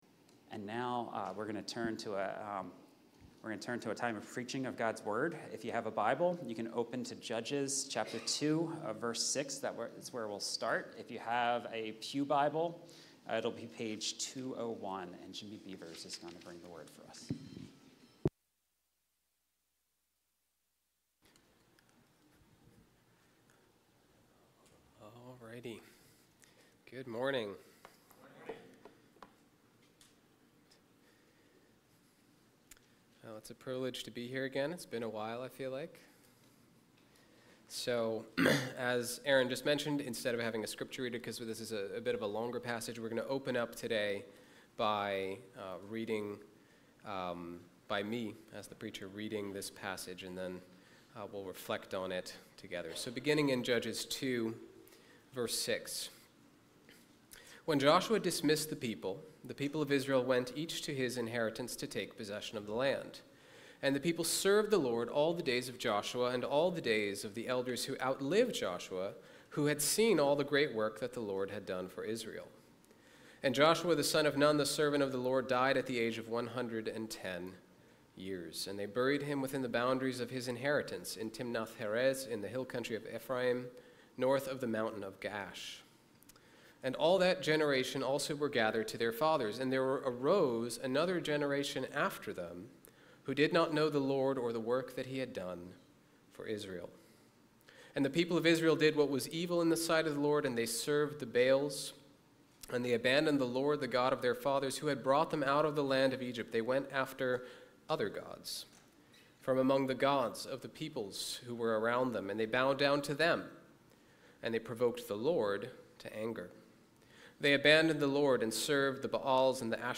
Sermons - Grace City Church of the Northeast